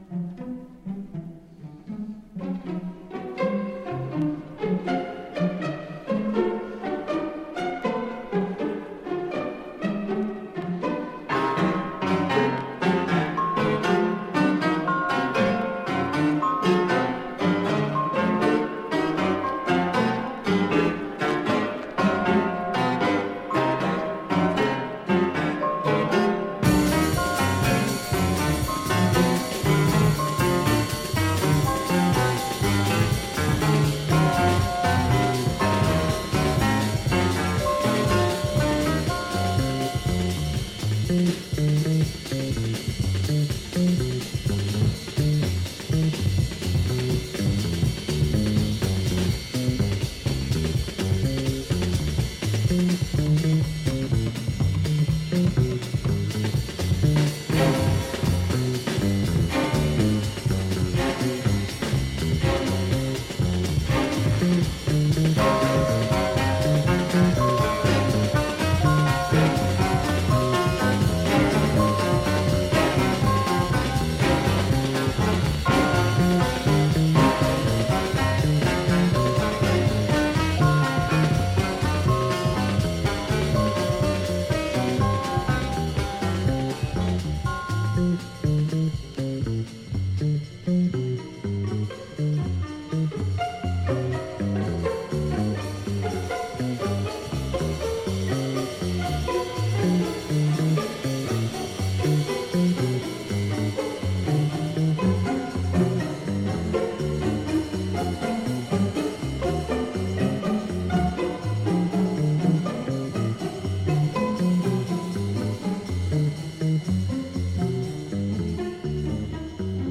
Tension!
Soundtrack Library